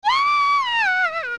scream when falling from a great height, though unlike Link, Ruto doesn't take damage.
OOT_Ruto_Scream.wav